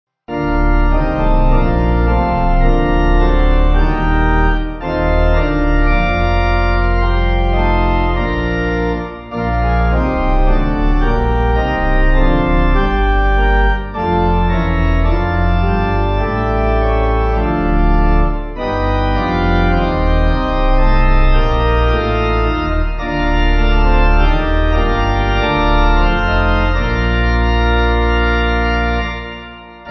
Organ
(CM)   5/Bb